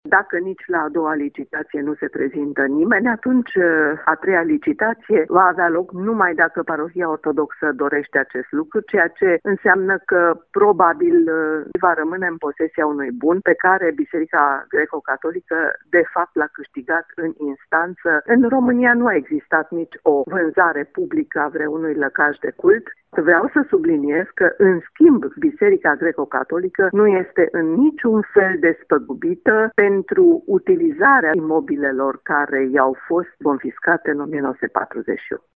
Situația este un abuz fără precedent, spune Smaranda Enache: